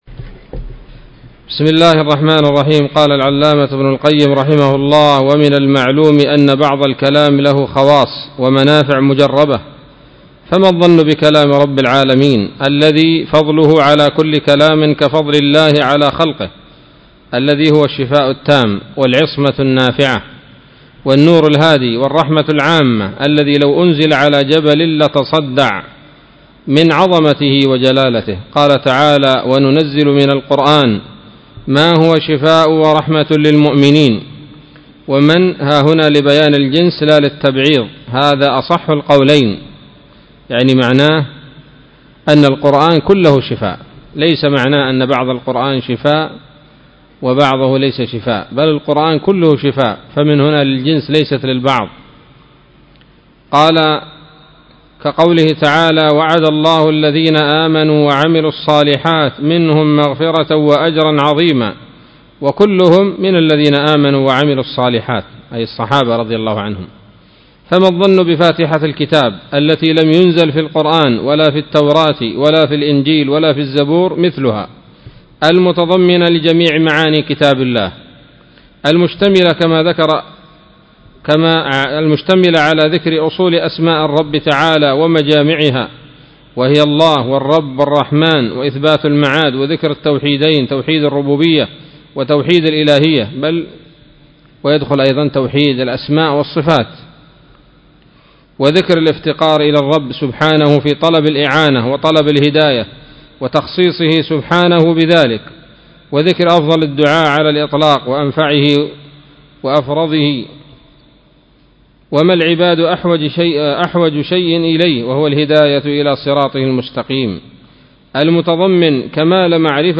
الدرس الخمسون من كتاب الطب النبوي لابن القيم